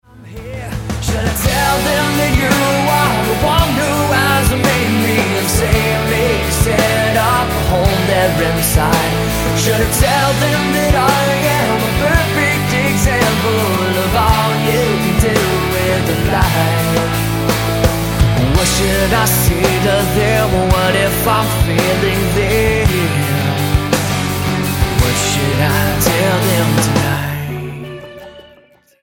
STYLE: Roots/Acoustic
acoustic/rock thrash mix
Atmospheric and vulnerable.